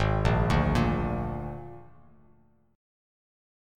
G#sus2b5 chord